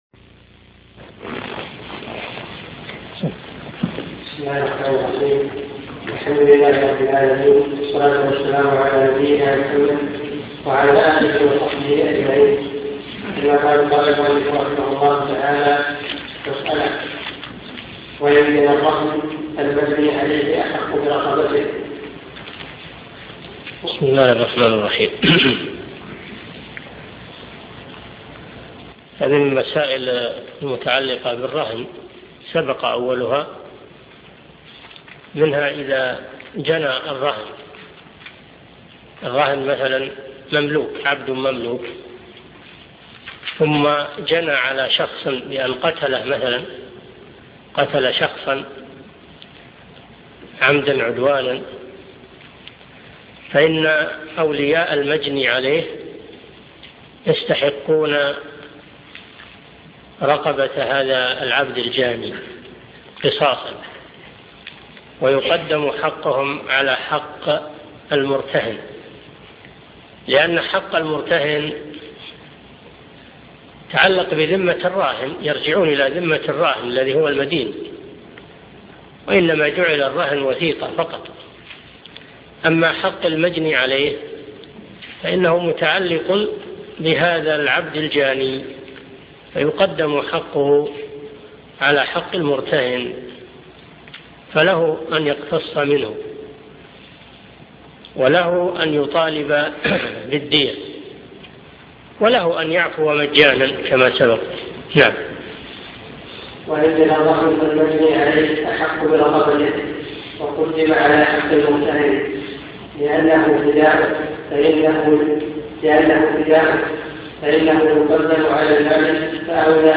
عمدة الأحكام في معالم الحلال والحرام عن خير الأنام شرح الشيخ صالح بن فوزان الفوزان الدرس 59